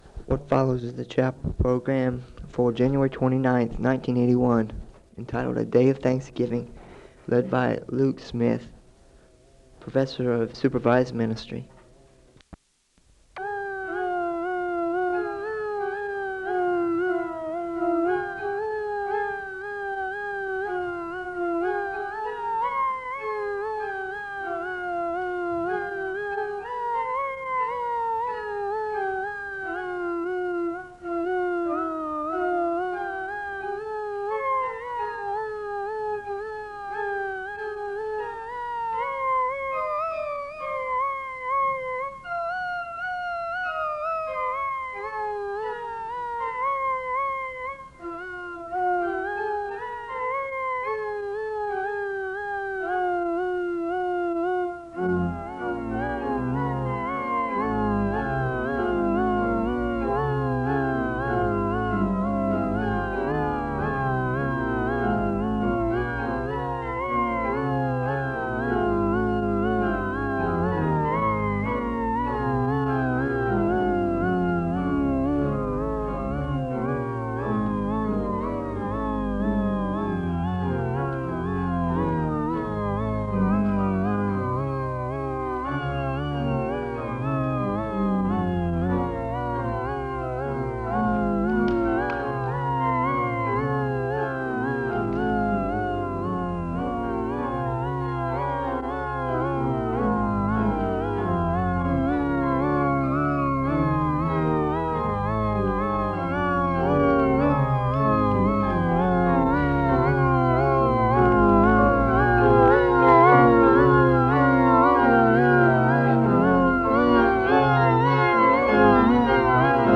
The service begins with organ music (00:00-04:32). The speaker gives a liturgical reading and a word of prayer (04:33-06:43). The choir sings a song of worship (06:44-10:04).
SEBTS Chapel and Special Event Recordings